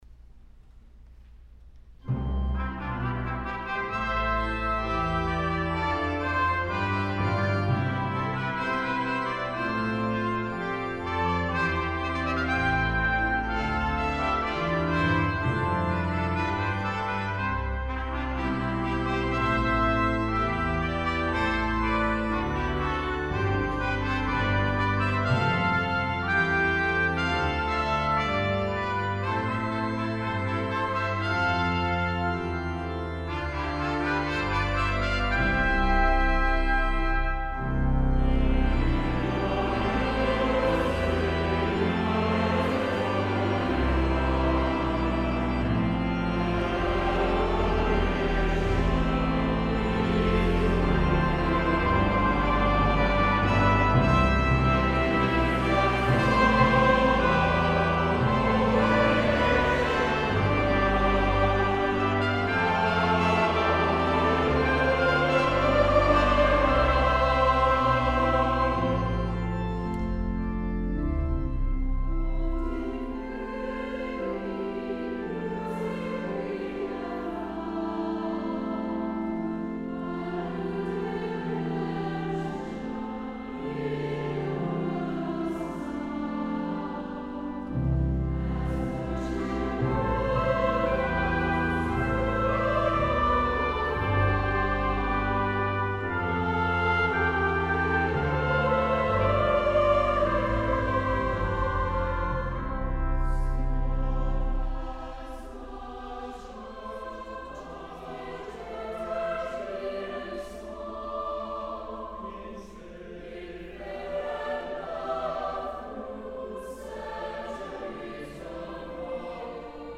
when-in-our-music-god-is-glorified-alcm-event-at-grace-river-forest-2007.mp3